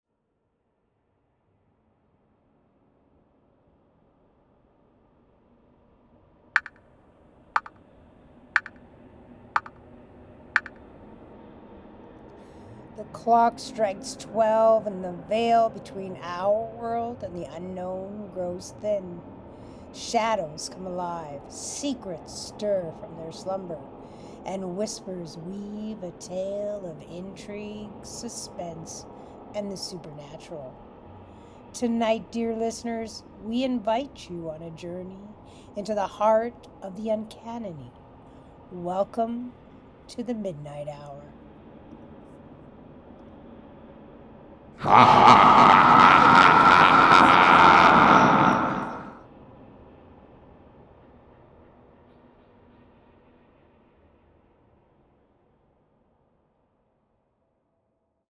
Recording 4: In this version I was able to clean up sections, change fade in and out of sounds for impact, and change the volume of each sound to ensure my voice is the main element to make a final recording that I really liked for my introduction.
HOST (with a smooth, foreboding tone):